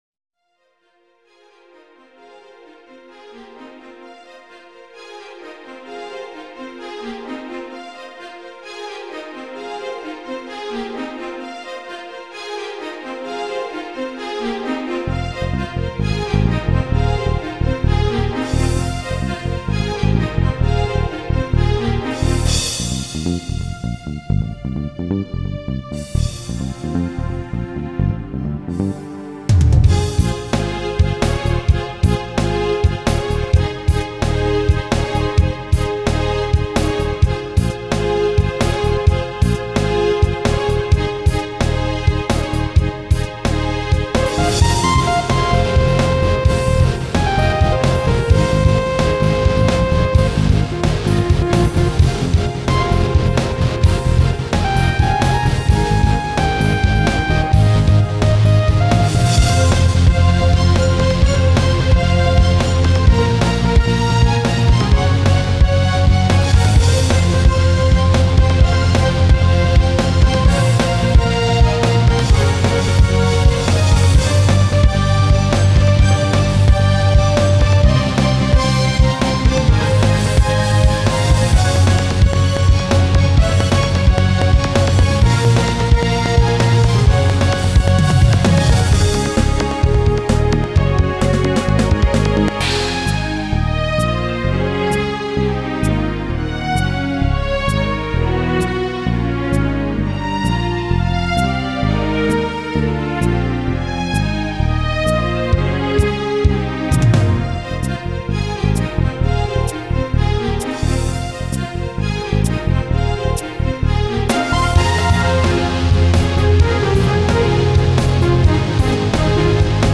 4/4 time, 130 bpm